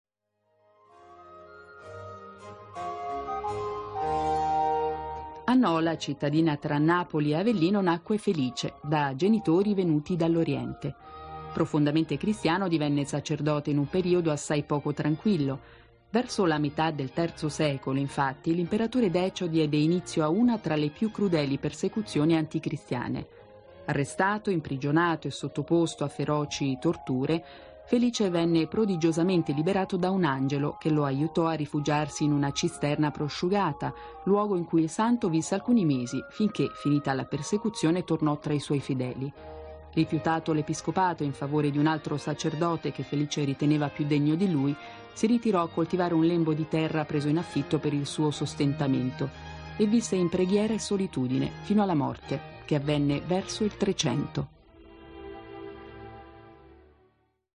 Liturgia del Santo Patrono
Domenica 25, la Propositura di S. Felice a Ema, ha festeggiato la ricorrenza di S. Felice Santo Patrono.